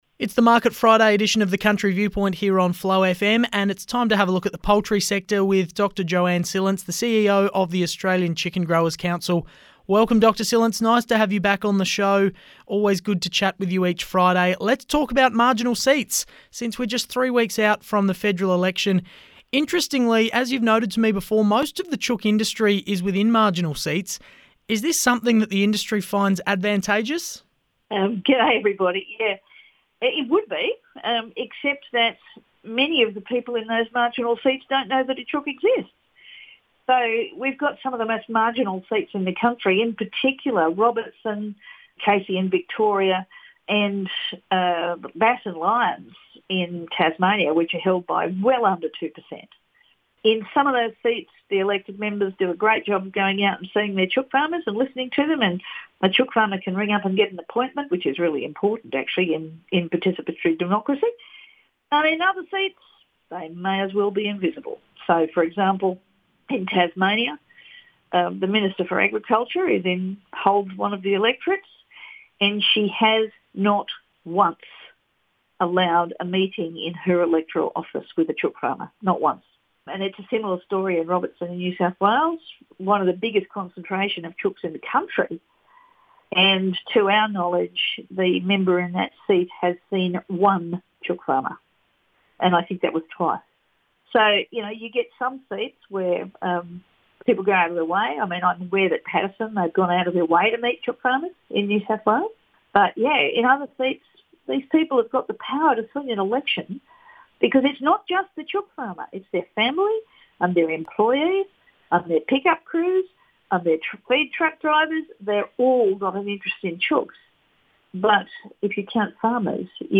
Radio Interviews on Flow FM, South Australia